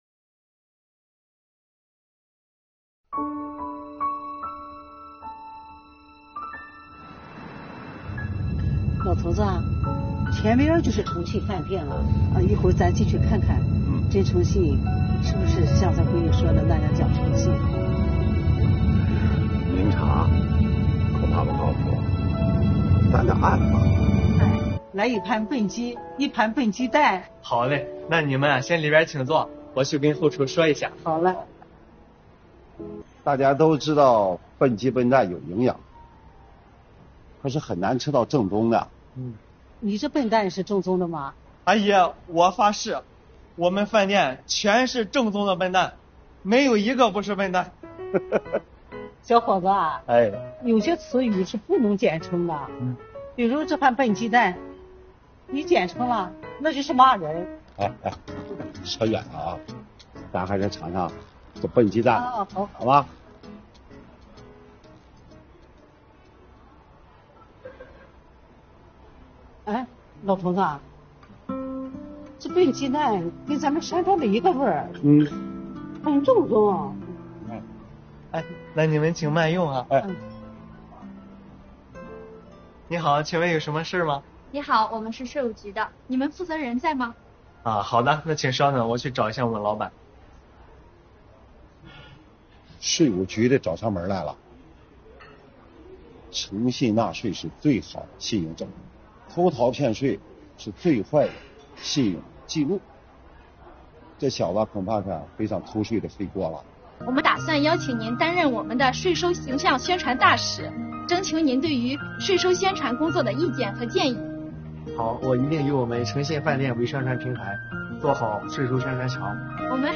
一天，他们去女儿男朋友甄诚信开的诚信饭店去“暗访”，考察一下他是否讲诚信。3分钟情景剧，看老两口如何“暗访”未来女婿~